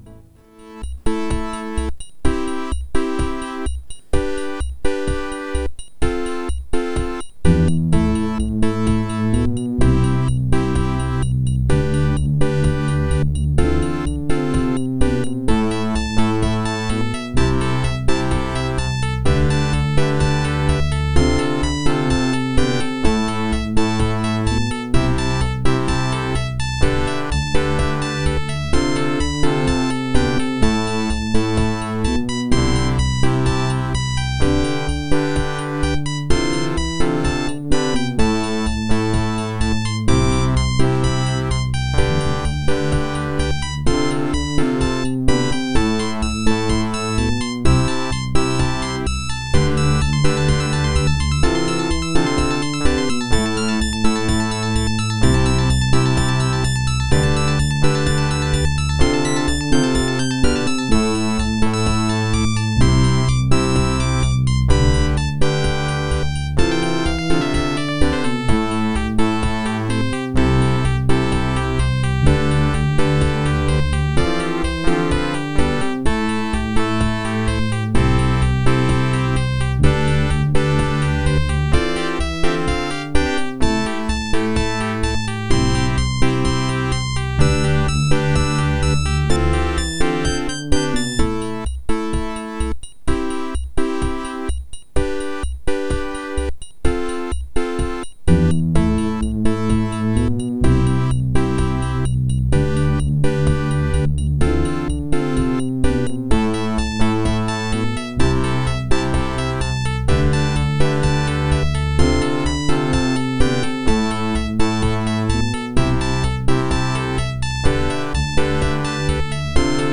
486DX-33, Sound Blaster
Weighted SnR (Speaker Out): -71 dB
(Speaker out on left, Line Out on right)